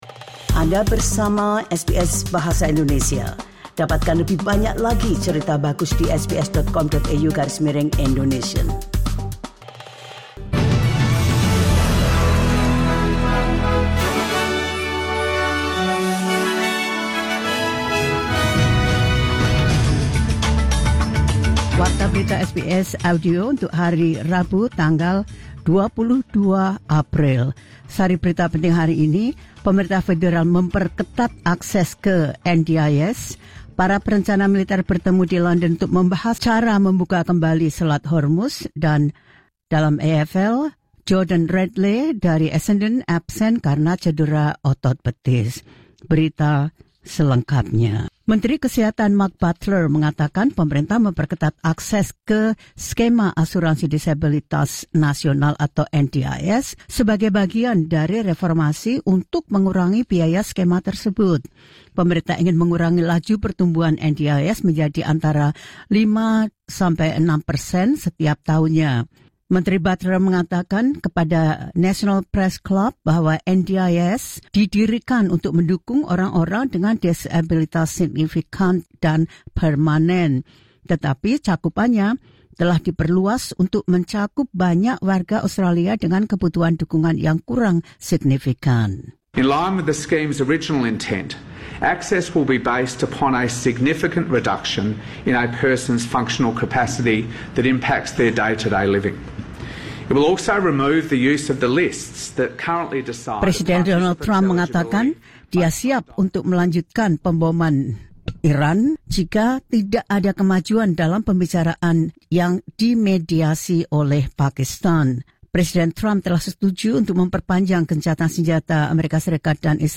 The latest news SBS Audio Indonesian Program – Wed 22 April 2026